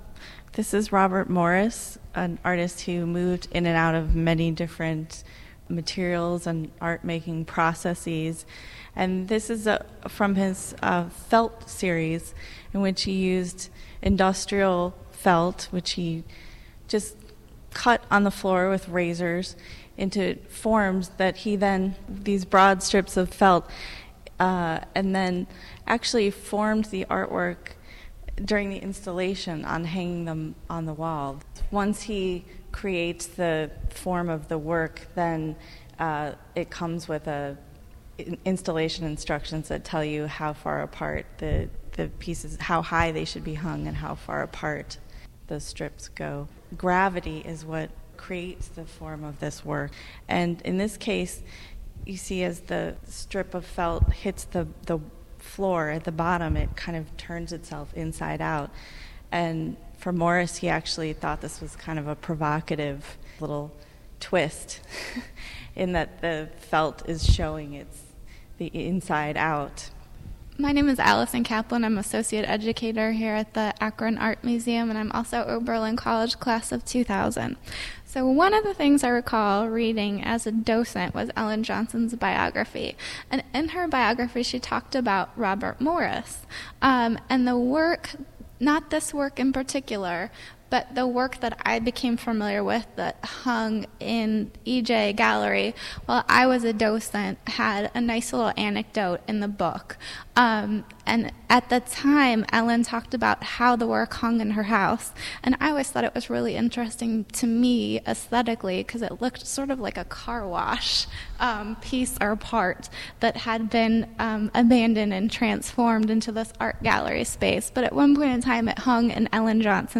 These podcasts are taken from conversations in the Akron galleries